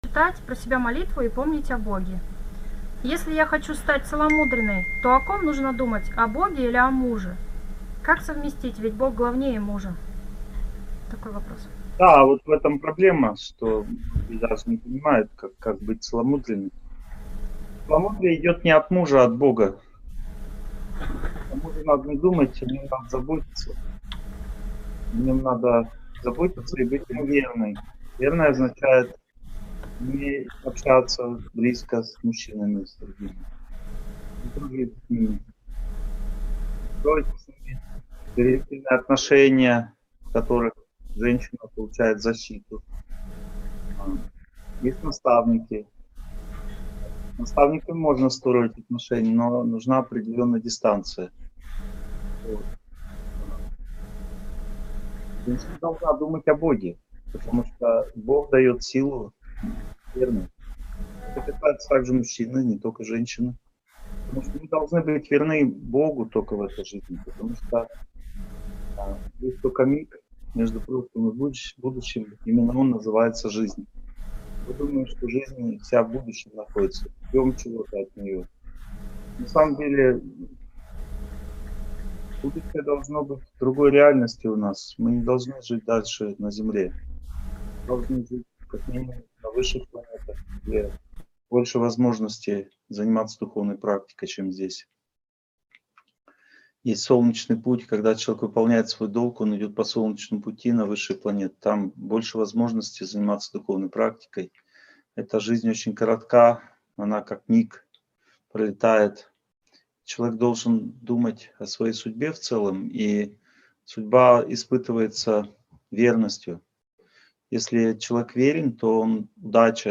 Верность и целомудрие (онлайн-семинар, 2021)